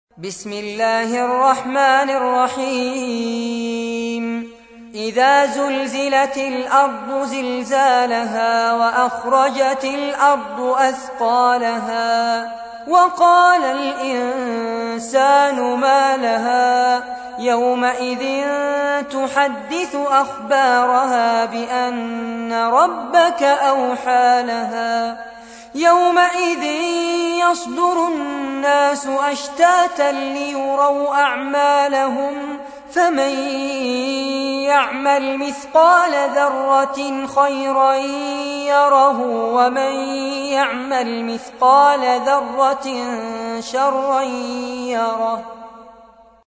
تحميل : 99. سورة الزلزلة / القارئ فارس عباد / القرآن الكريم / موقع يا حسين